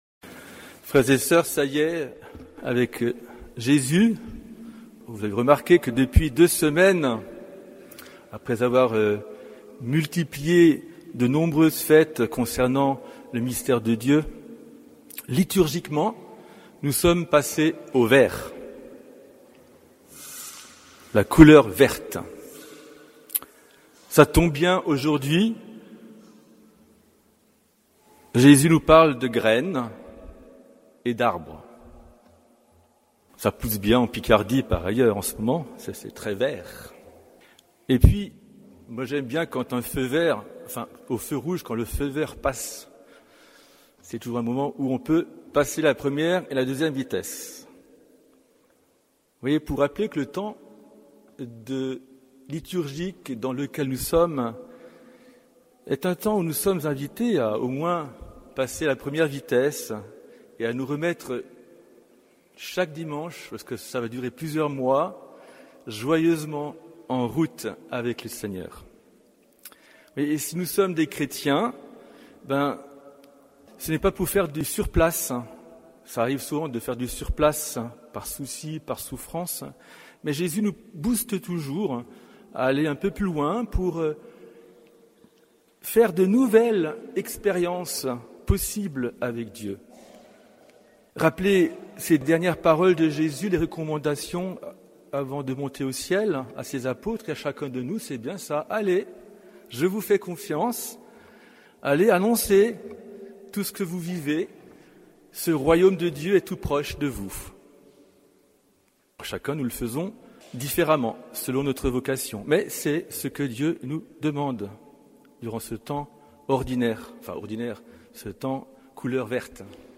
Homélie du 11e dimanche du Temps Ordinaire